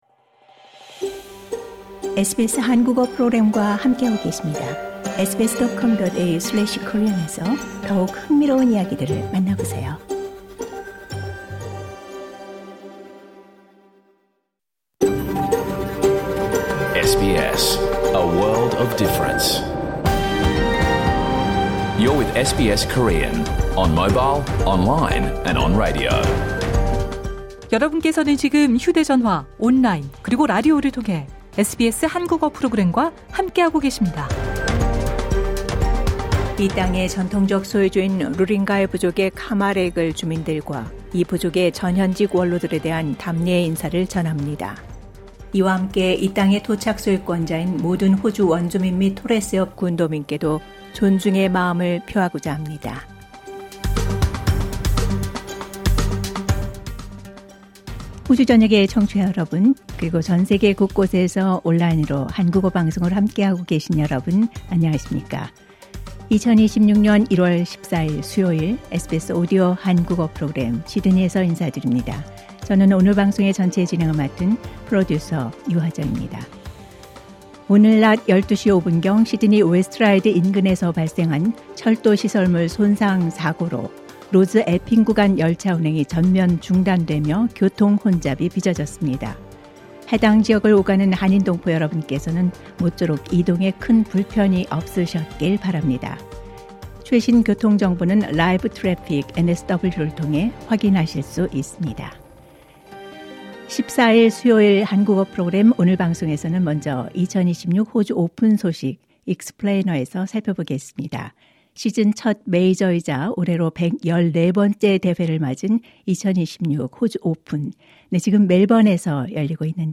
2026년 1월 14일 수요일에 방송된 SBS 한국어 프로그램 전체를 들으실 수 있습니다.